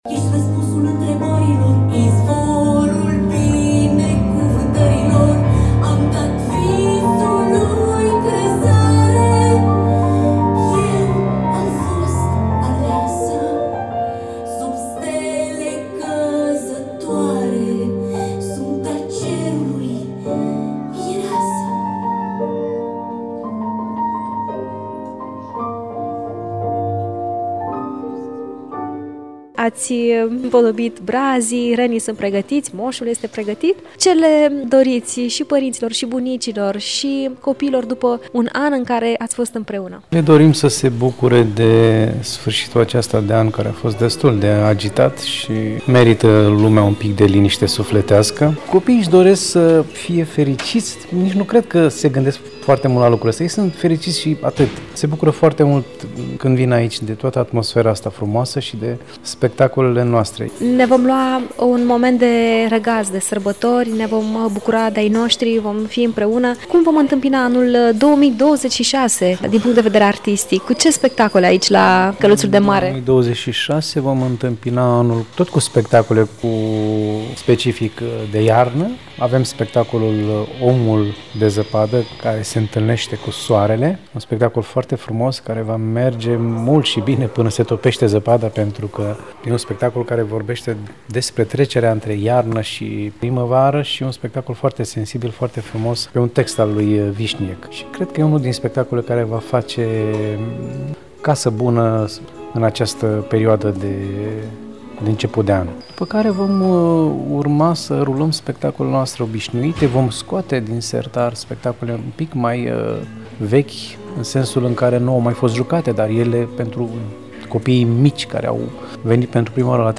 în dialog cu regizorul